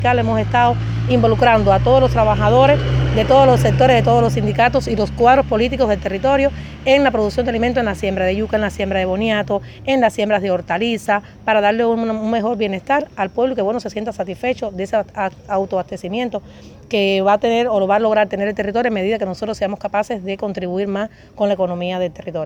Entrevistas Isla de la Juventud Ordenamiento